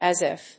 アクセントas ìf